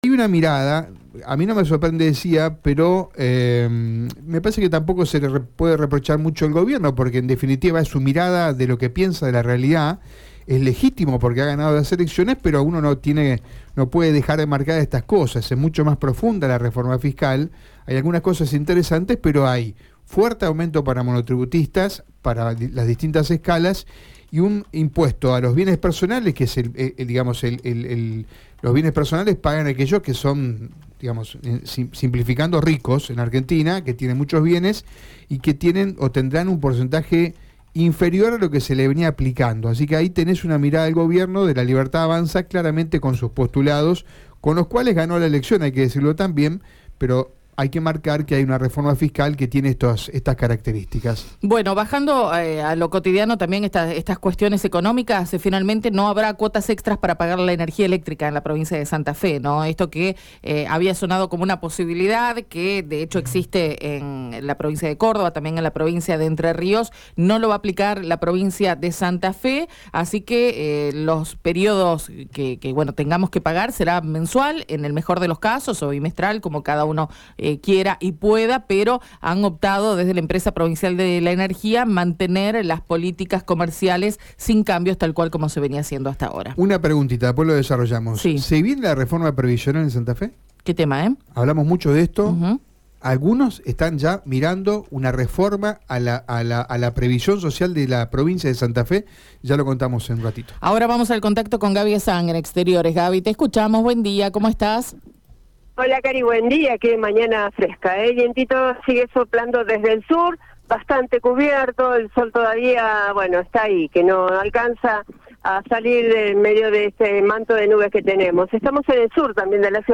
Lo confirmó , en conferencia de prensa, Virginia Coudannes, Secretaria de Gestión Institucional del Ministerio de Justicia y Seguridad de Santa Fe